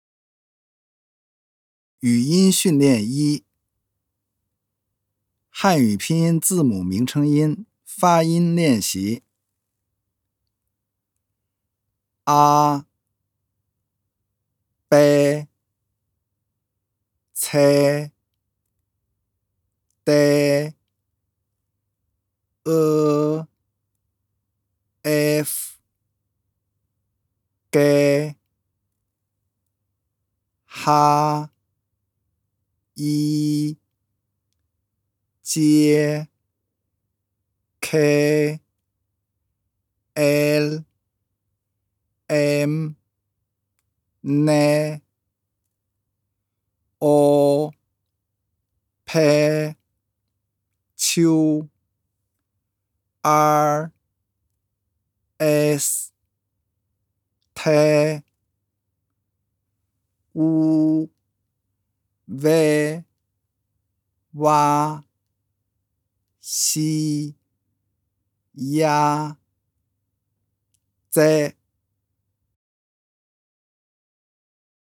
第三讲语音训练一-男声